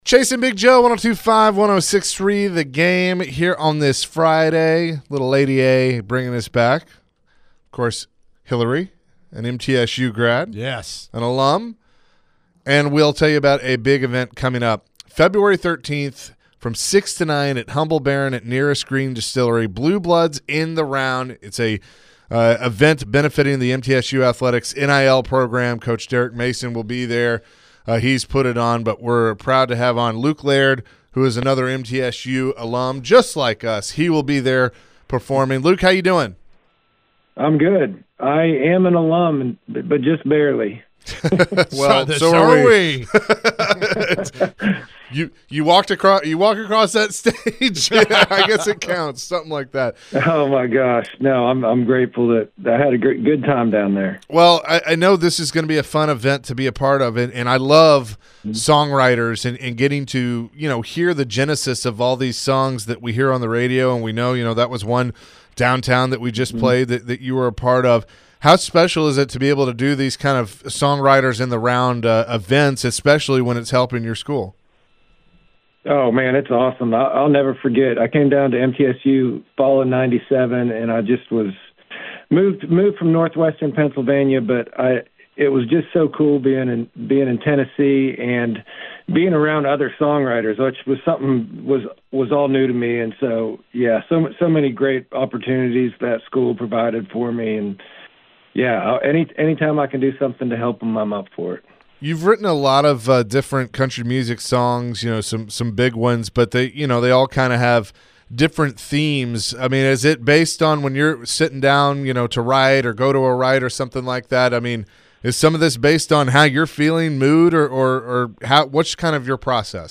The guys chatted with country music songwriter Luke Laird. Laird will be a part of the Blue Bloods NIL Collective for MTSU football. Luke shared some cool stories about songwriting.